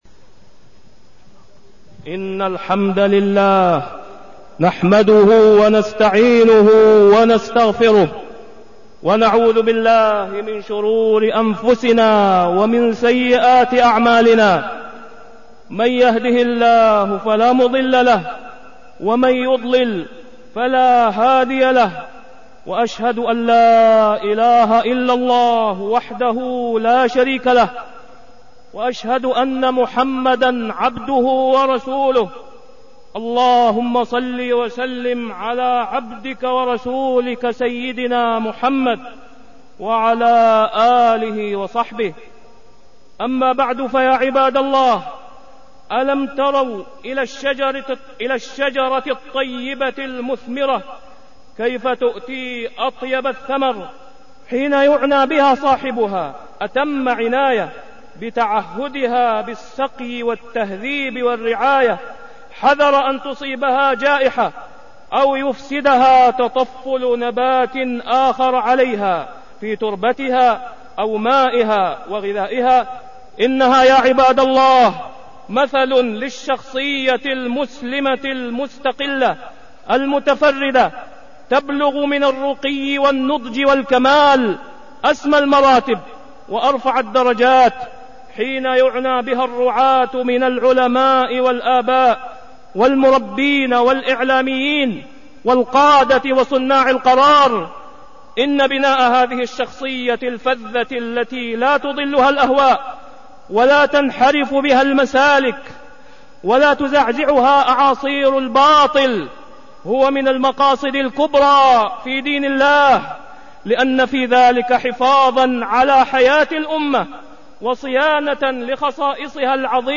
تاريخ النشر ٢٣ جمادى الآخرة ١٤١٨ هـ المكان: المسجد الحرام الشيخ: فضيلة الشيخ د. أسامة بن عبدالله خياط فضيلة الشيخ د. أسامة بن عبدالله خياط الشخصية المسلمة